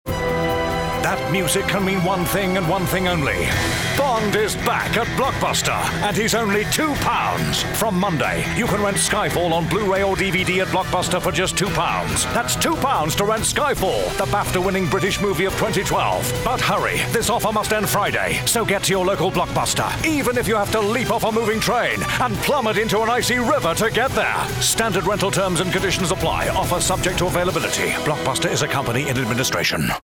Examples of Commercial Voiceover Work – Performed By Peter Dickson